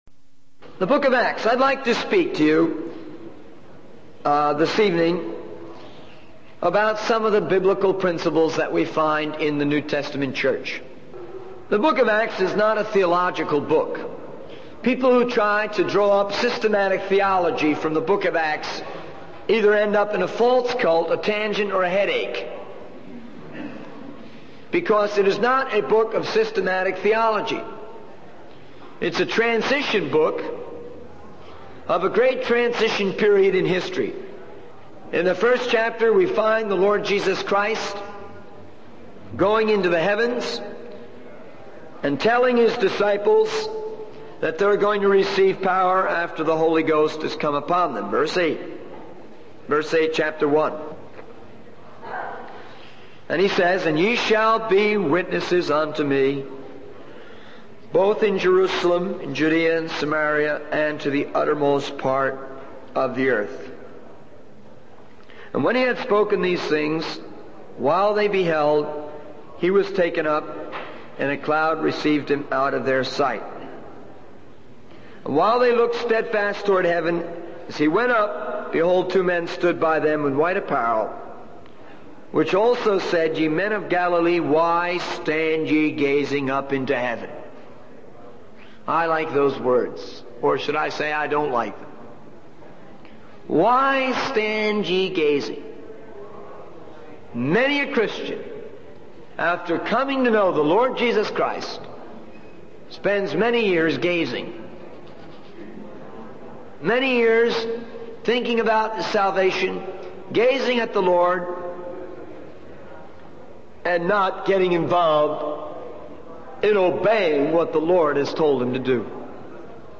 In this sermon, the preacher focuses on the book of Acts, which he describes as a transition book in a great transition period in history. He highlights the ascension of Jesus into heaven and his promise to send the Holy Spirit to empower his disciples.